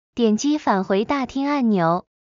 点击返回大厅按钮.MP3